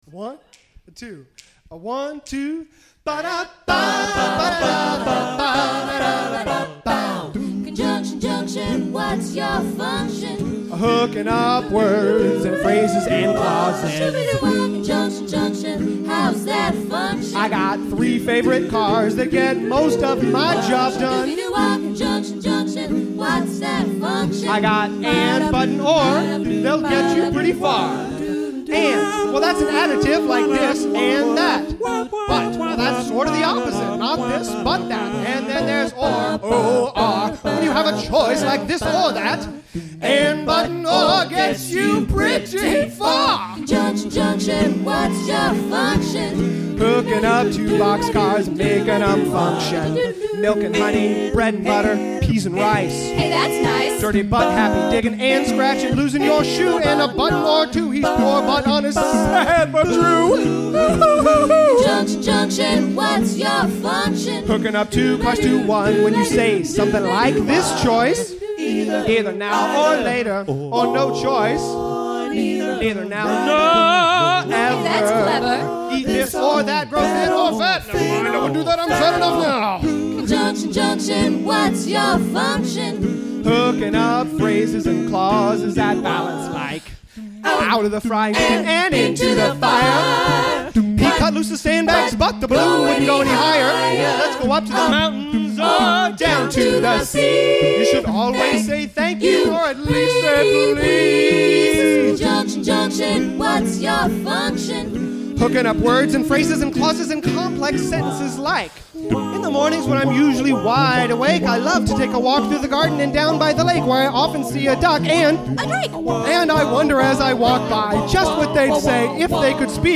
November 11, 2000 at the Seattle Art Museum